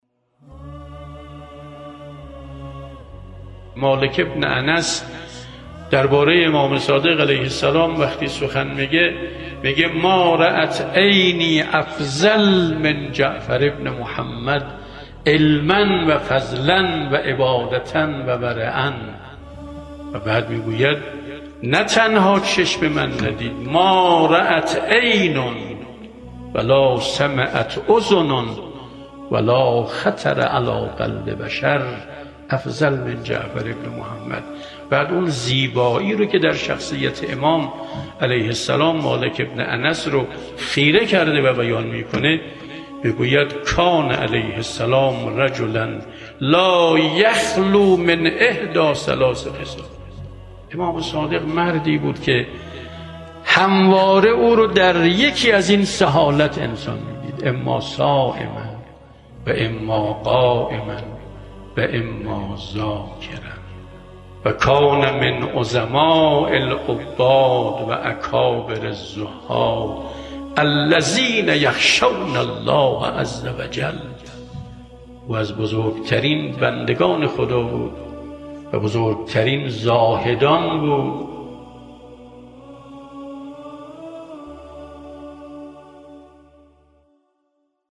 فرازی از سخنرانی